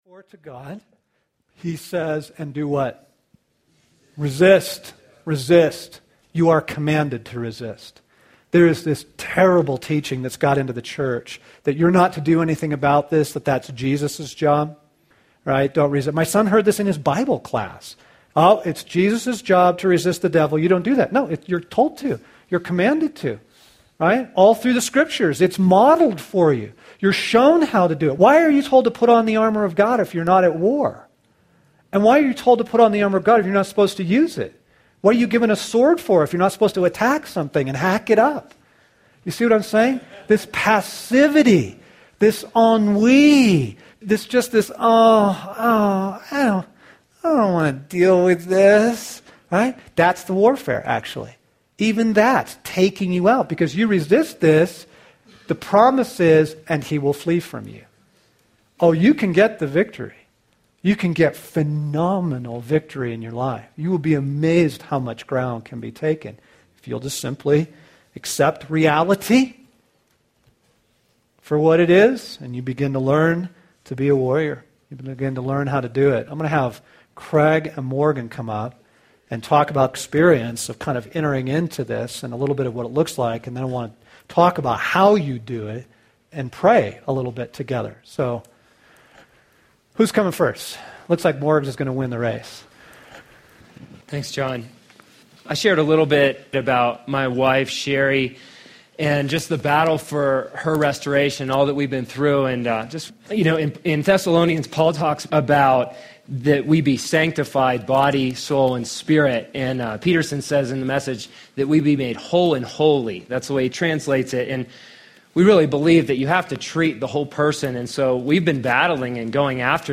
recorded live at our Wild at Heart Boot Camp and Advanced retreats